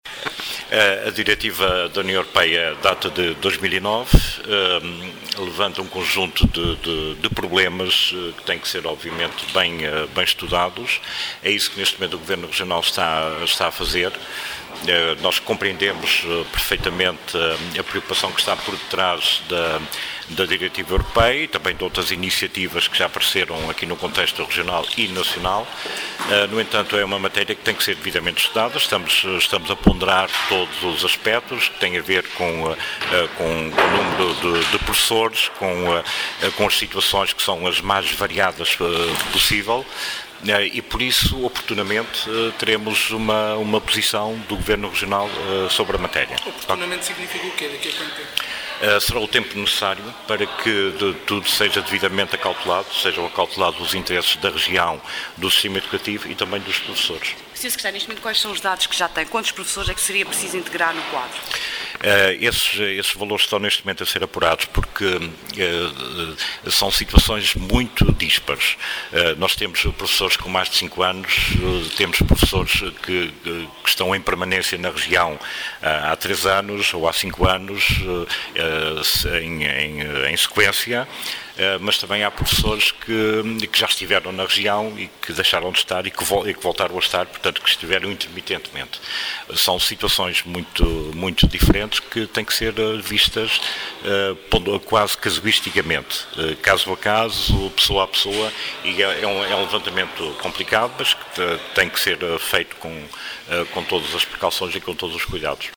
Luiz Fagundes Duarte, que foi ouvido pela Comissão dos Assuntos Sociais da Assembleia Legislativa da Região Autónoma dos Açores, disse que o Executivo “está a ponderar todos os aspetos que têm a ver com o número de professores e as suas mais variadas situações” e, oportunamente, terá uma posição sobre a matéria.